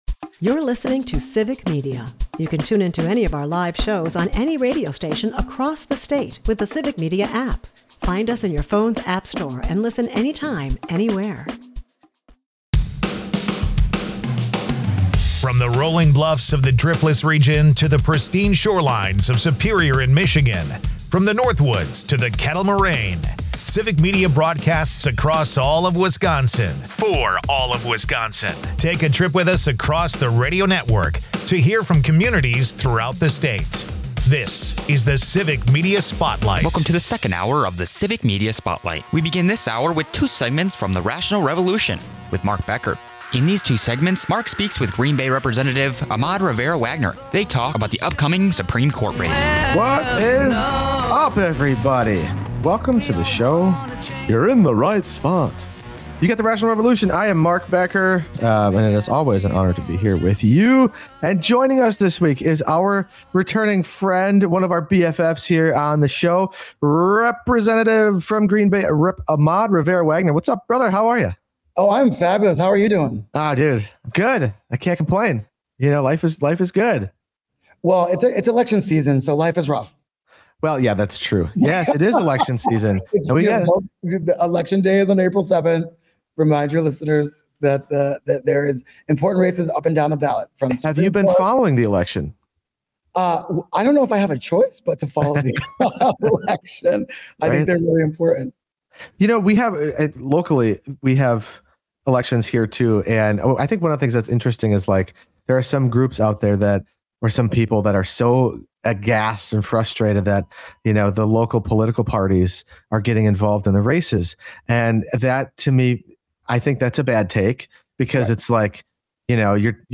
It’s a mix of real talk and rock talk—covering everything from civic responsibility to keeping your strings in tune.